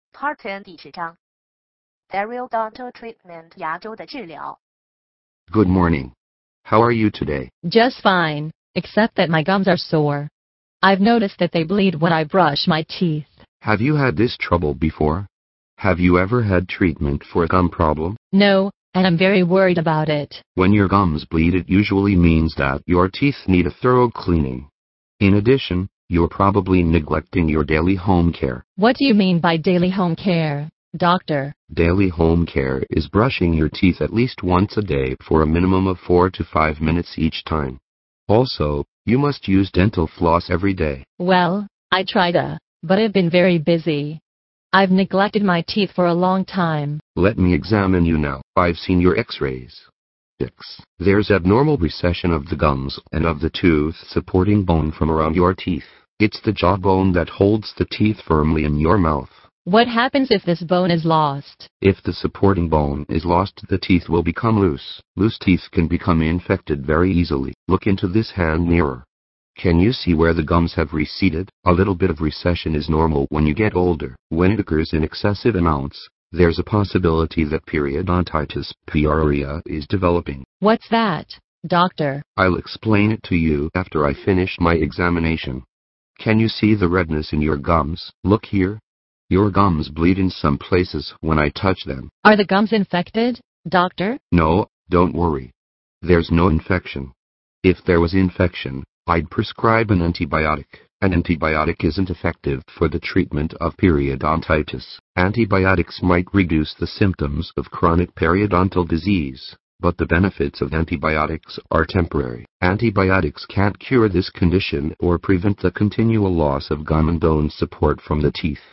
收录了口腔医学医患、医助之间的对话，非常适合医学生、临床医务人员练习专业口语和听力，在欧洲很受欢迎。